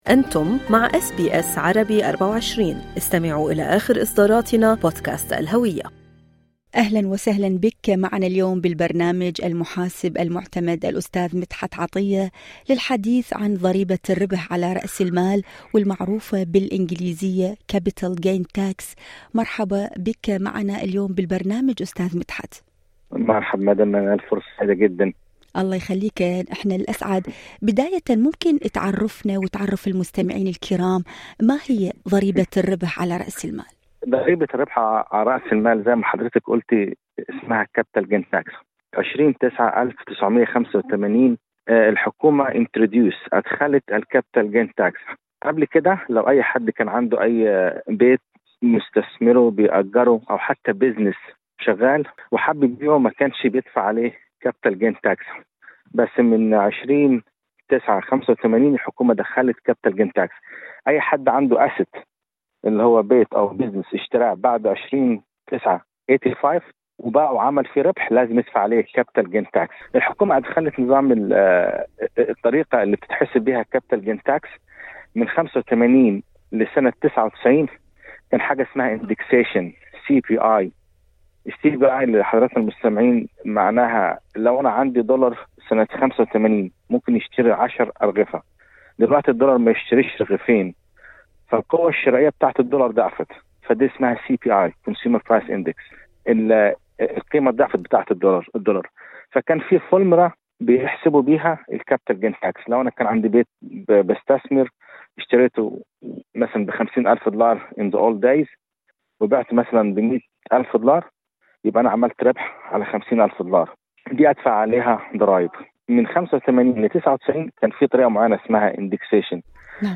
المقابلة الصوتية